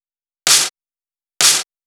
VTDS2 Song Kits 128 BPM Pitched Your Life